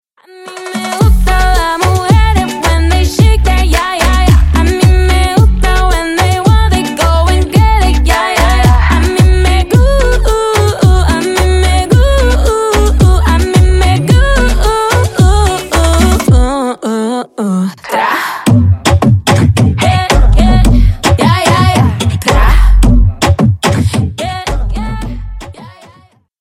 Латинские Рингтоны
Поп Рингтоны